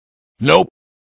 Hiệu ứng âm thanh NOPE - Hiệu ứng âm thanh edit video